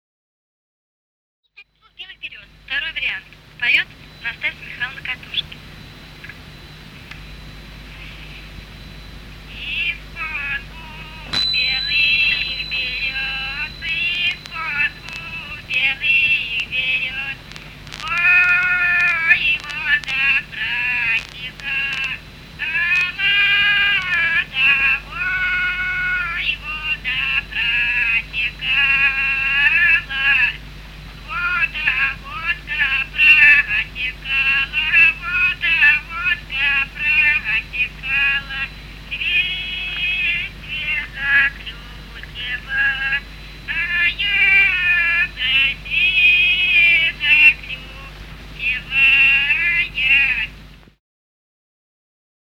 Русские народные песни Владимирской области 20б. Из-под двух белых берёз (хороводная) с. Коровники Суздальского района Владимирской области.